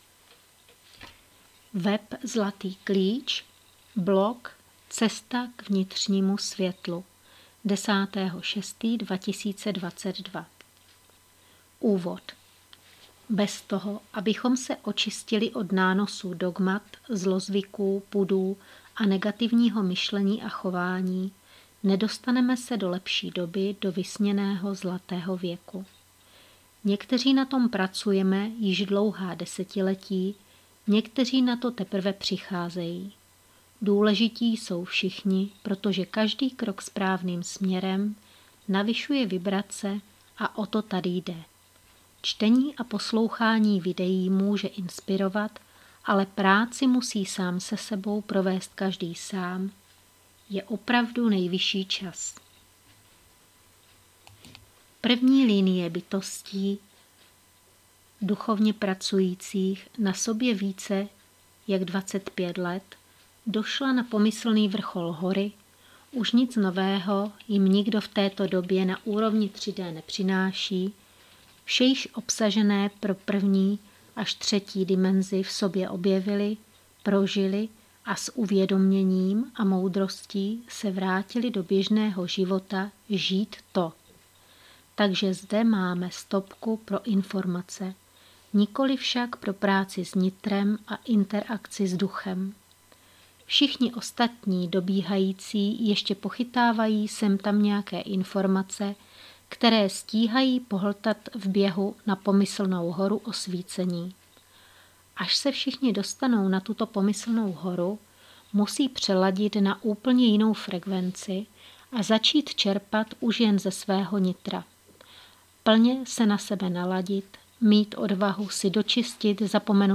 namluvený blog, audio blog, MP3 blog, meditace, mluvené slovo, audio